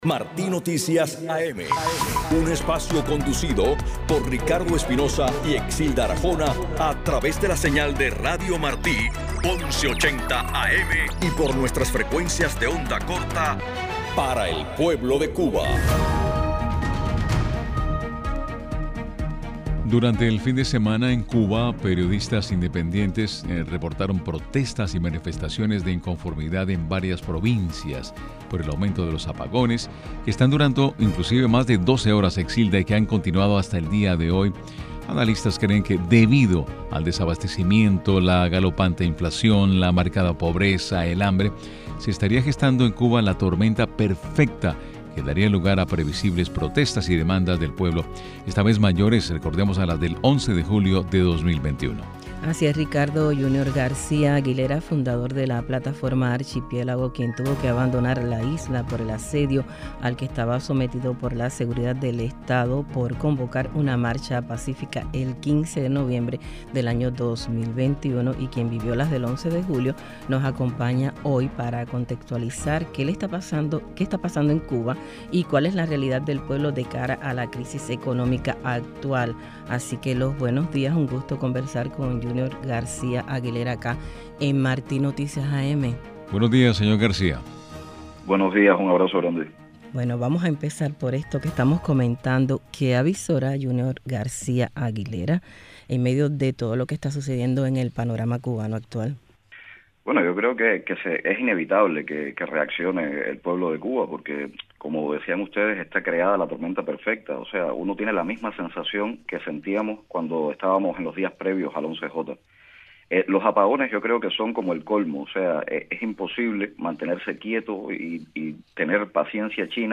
Declaraciones del activista y dramaturgo Yunior García Aguilera al informativo Martí Noticias AM